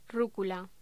Locución: Rúcula
voz
Sonidos: Voz humana